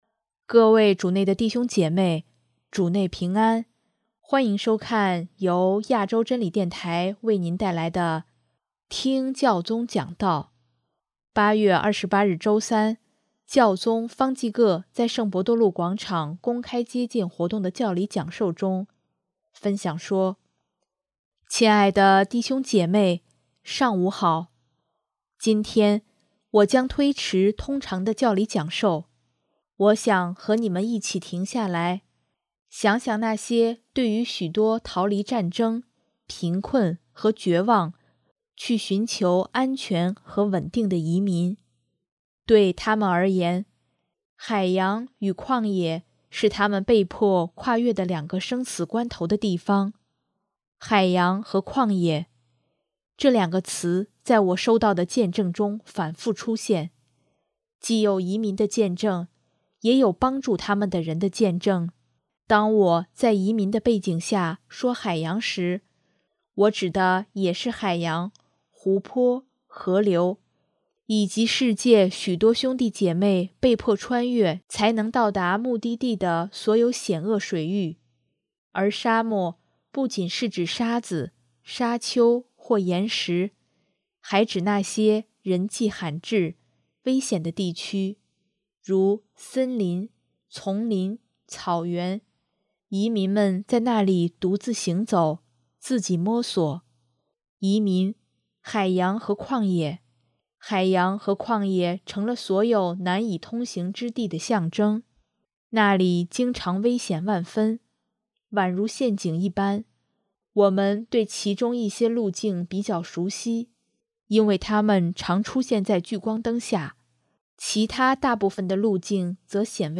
8月28日周三，教宗方济各在圣伯多禄广场公开接见活动的教理讲授中，分享说：